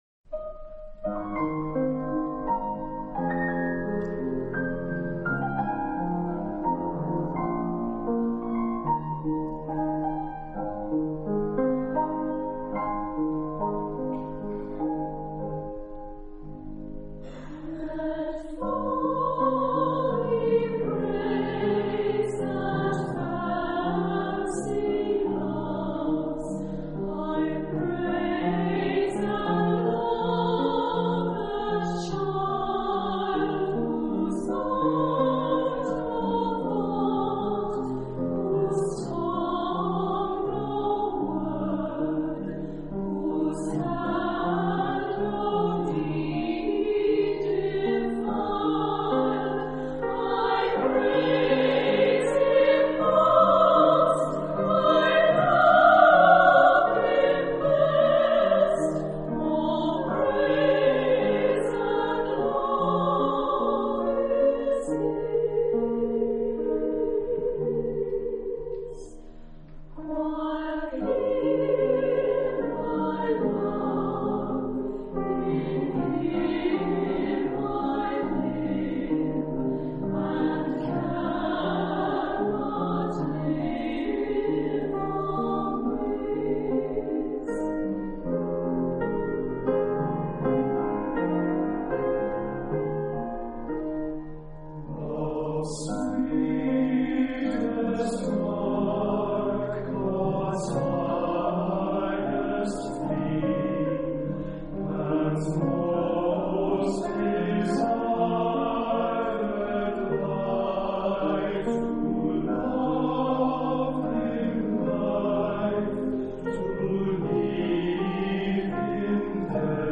SATB (4 voix mixtes).
Instrumentation : Clavier